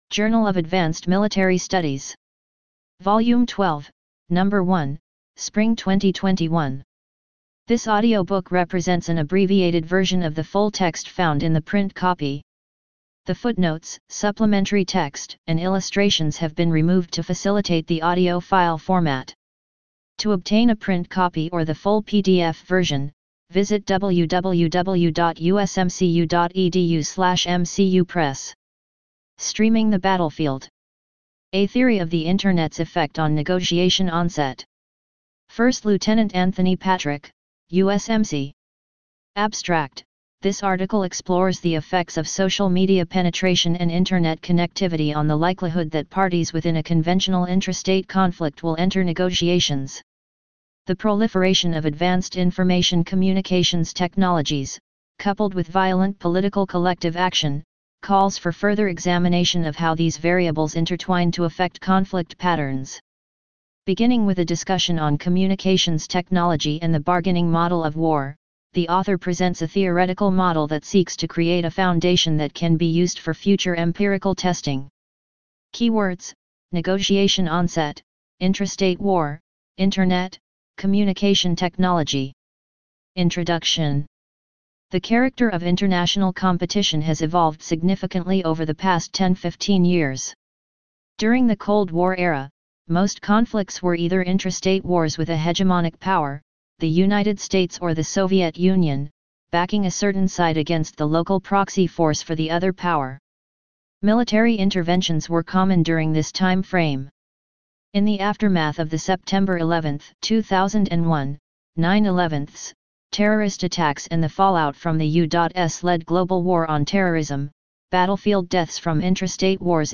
JAMS_Streaming the Battlefield_audiobook.mp3